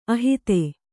♪ ahite